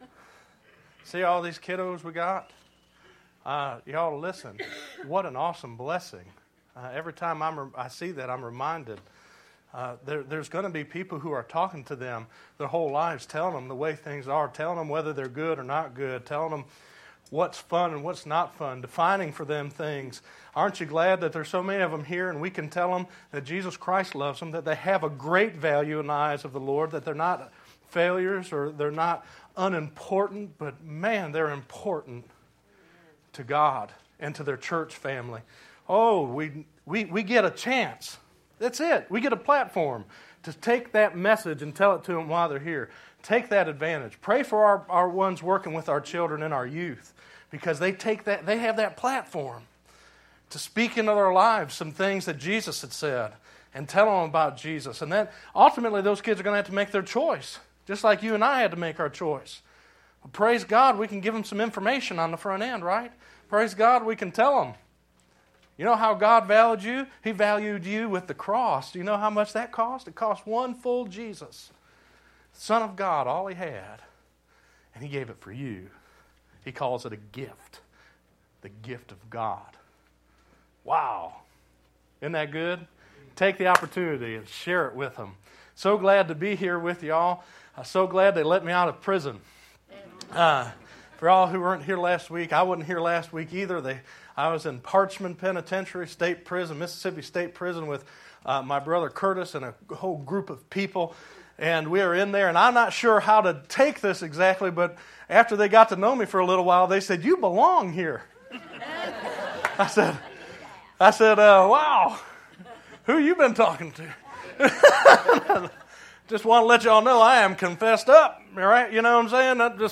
10_7_12_Sermon.mp3